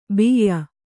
♪ biyya